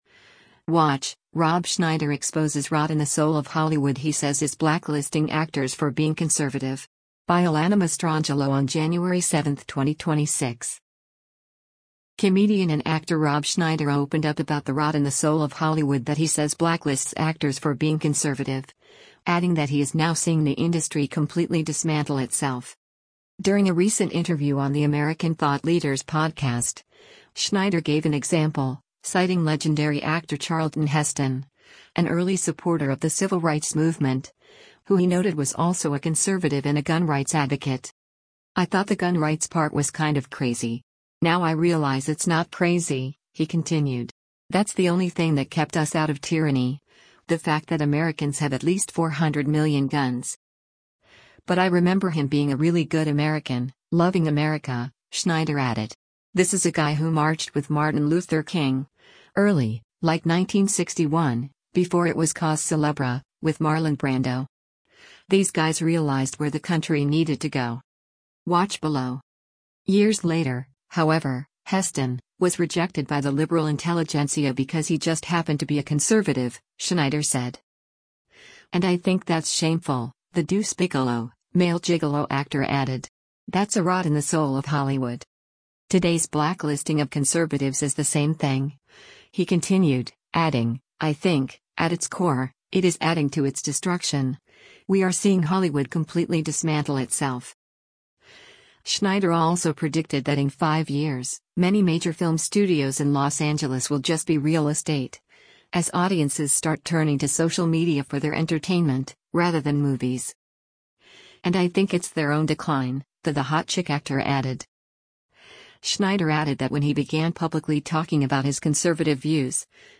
During a recent interview on the American Thought Leaders podcast, Schneider gave an example, citing legendary actor Charlton Heston, an early supporter of the civil rights movement, who he noted was also “a conservative and a gun rights advocate.”